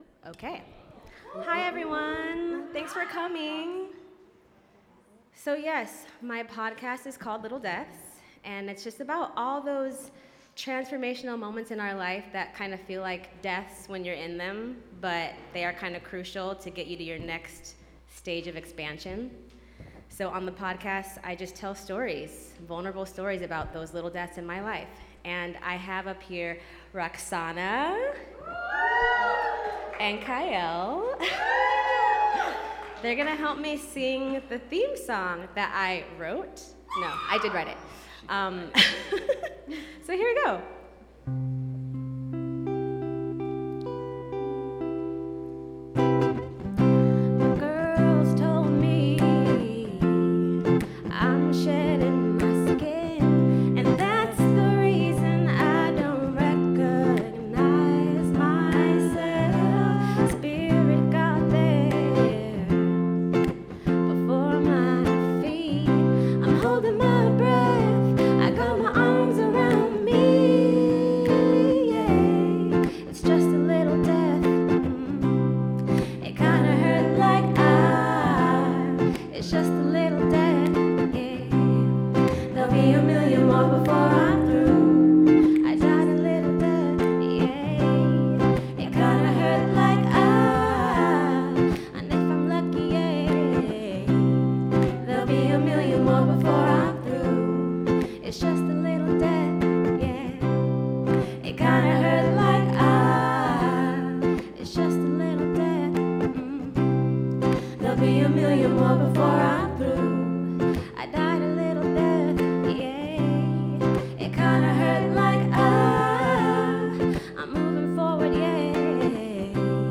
This was part of dublab and On Air Fest “Open House” broadcast that took place at the new dublab studios on November 5, 2022.
Live Performance